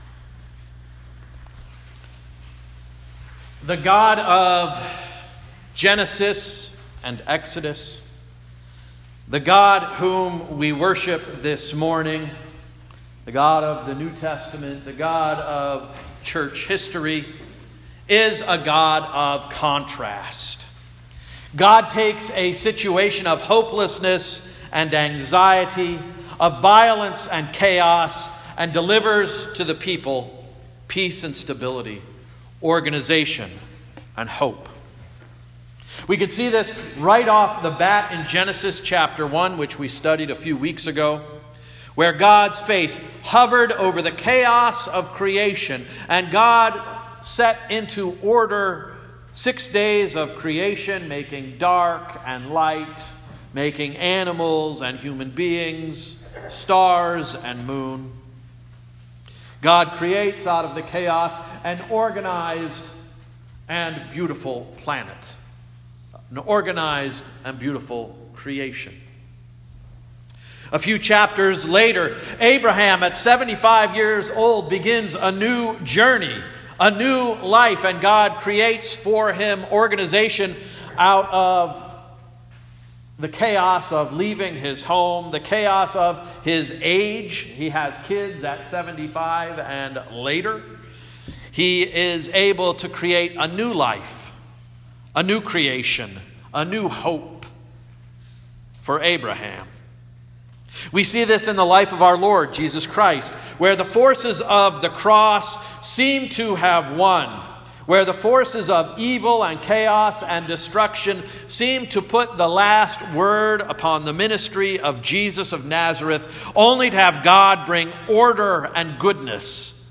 Sermon of September 30, 2018 — “Rough Road Ahead”